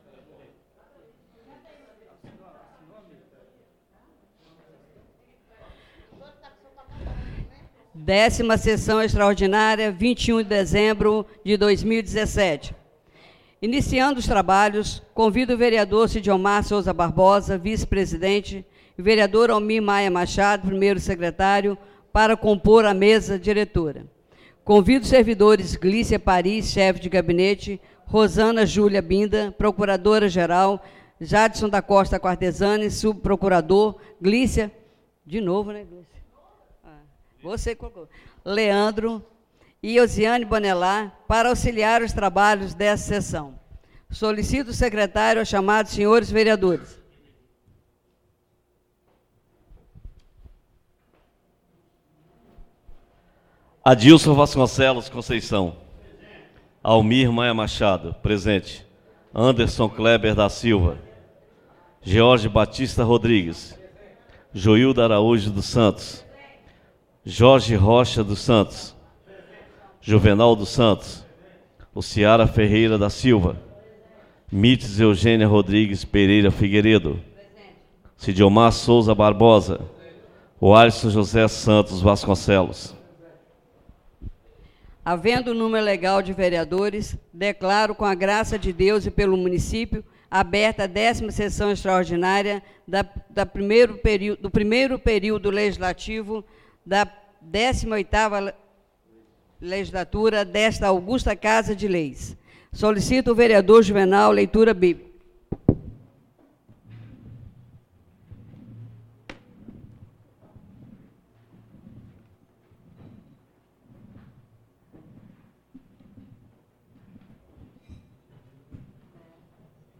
10ª (DÉCIMA) SESSÃO EXTRAORDINÁRIA PARA A DATA DE 21 DE DEZEMBRO DE 2017.